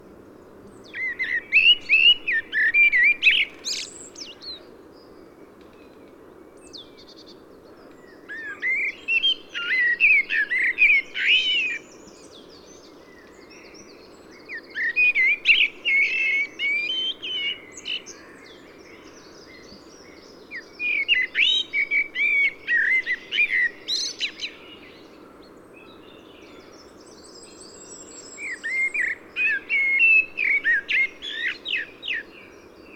Amsel
Hören Sie hier den Gesang der Amsel.
Amsel.mp3